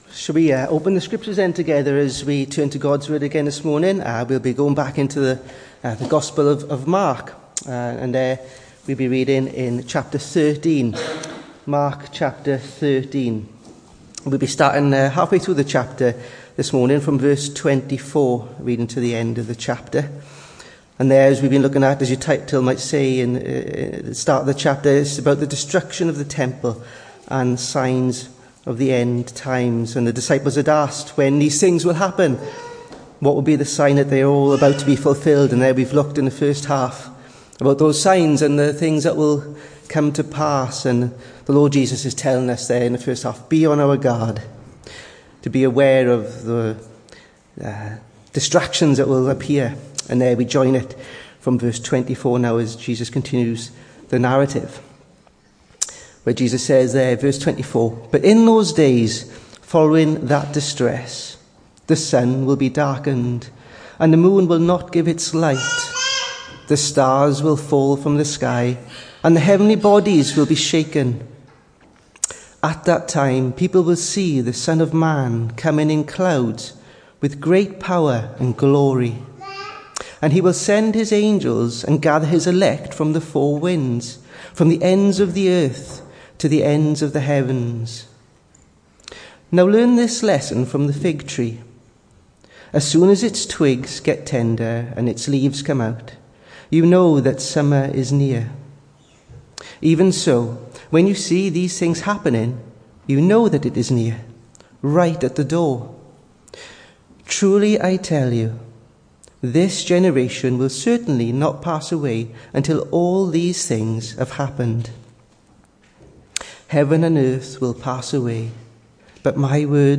Hello and welcome to Bethel Evangelical Church in Gorseinon and thank you for checking out this weeks sermon recordings.
The 5th of October saw us host our Sunday morning service from the church building, with a livestream available via Facebook.